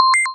coin-c.ogg